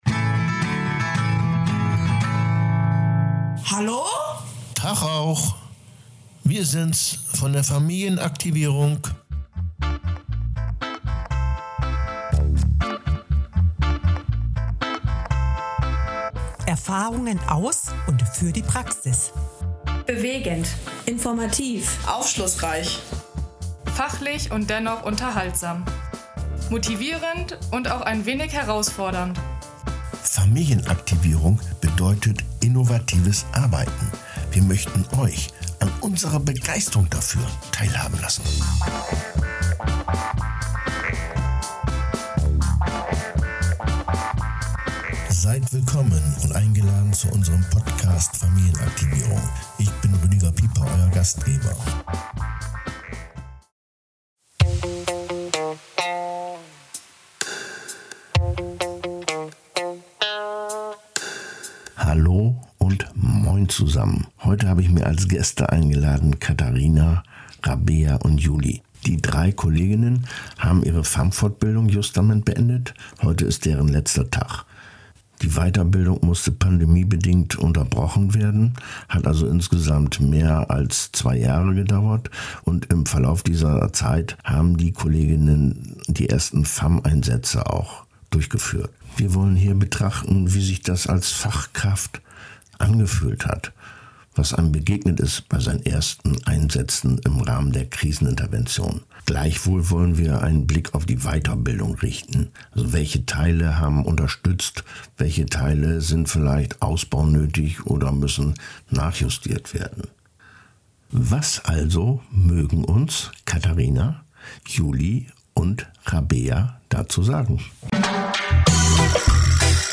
Teilnehmerinnen aus der FAM-Fortbildung tauschen sich über die Inhalte der Weiterbildung aus. Sie betrachten dabei auch die Wirkung auf ihre eigene Entwicklung und berichten über ihre Erfahrungen in ihren ersten FAM-Fällen.